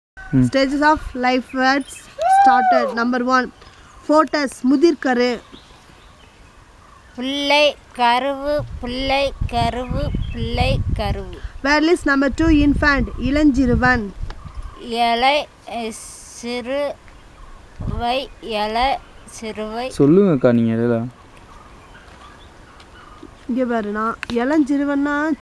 Elicitation of words about stages of life - Part 1